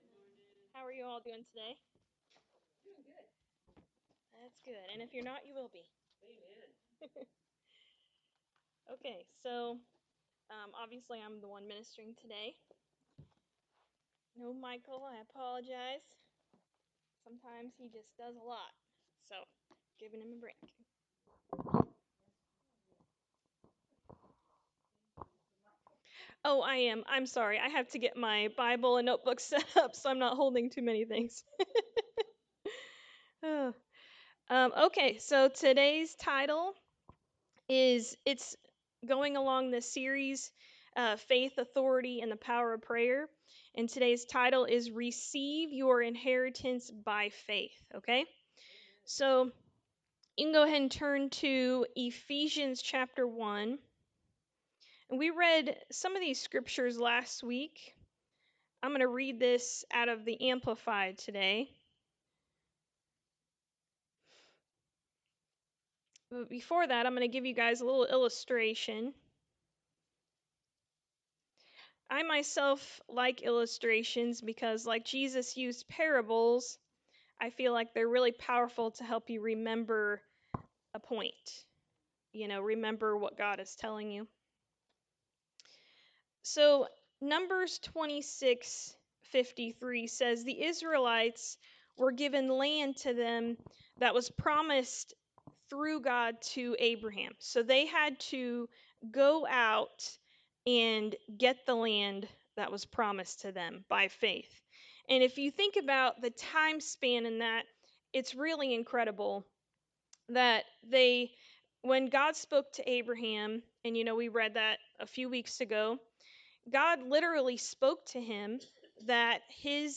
Sermon 6 – Receiving Your Inheritance by Faith
Service Type: Sunday Morning Service